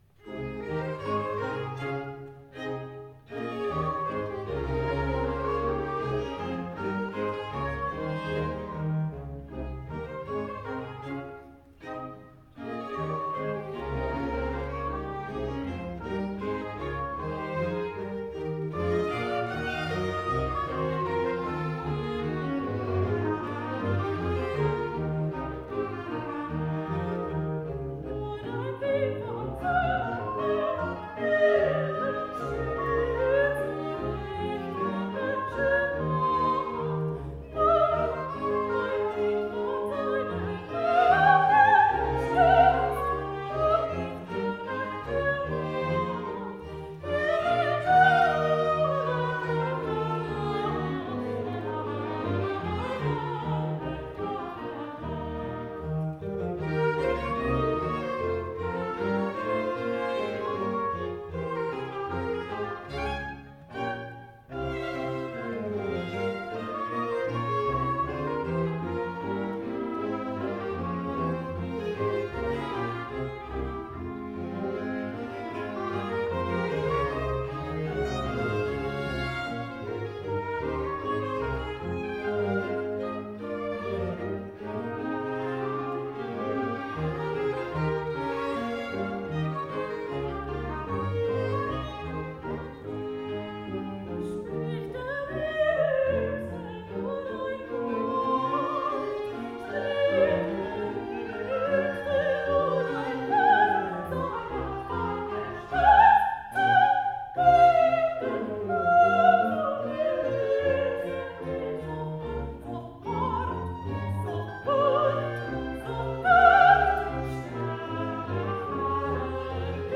Sopran
17-Arie-Sopran-Nur-ein-Wink-von-seinen-Händen.mp3